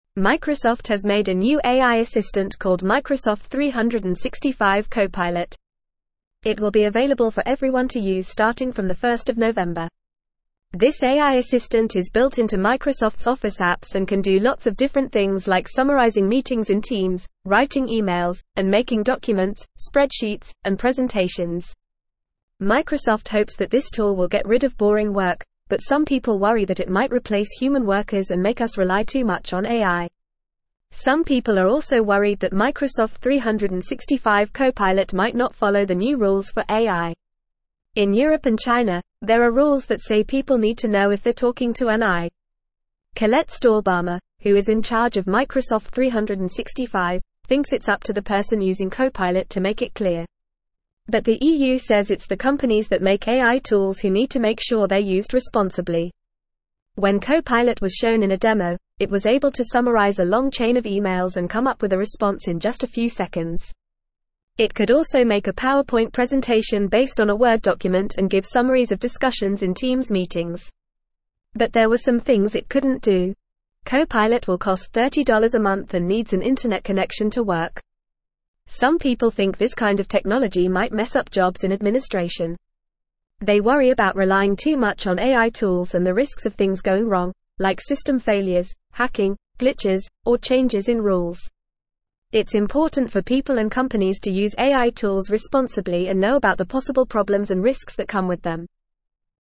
Fast
English-Newsroom-Upper-Intermediate-FAST-Reading-Microsoft-365-Copilot-AI-Assistant-Raises-Job-Displacement-Concerns.mp3